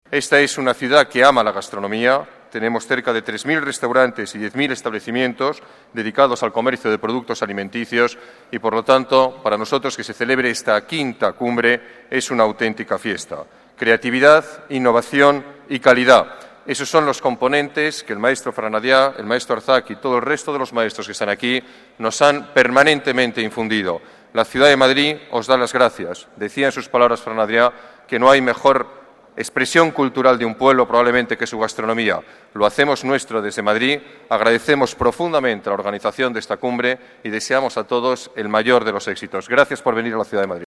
Nueva ventana:Declaraciones del alcalde sobre Madrid Fusión